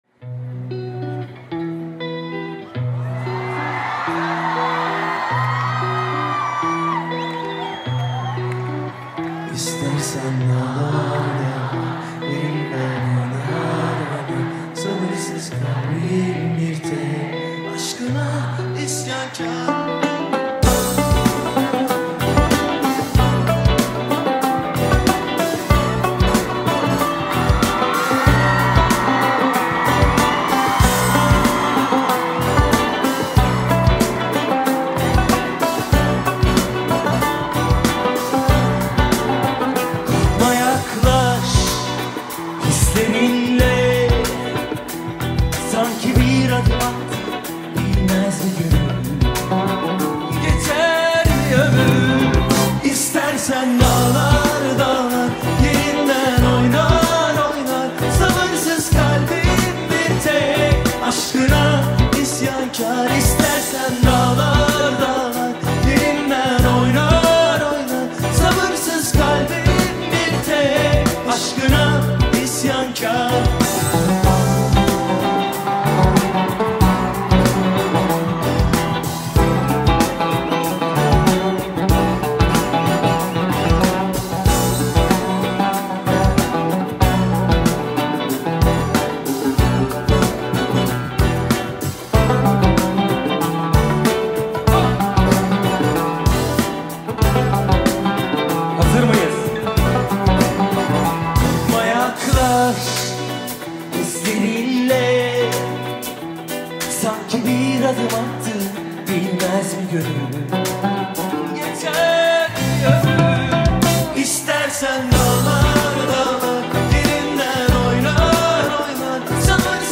Live At Zorlu
862 بازدید ۳۰ اردیبهشت ۱۴۰۳ آهنگ , آهنگ های ترکی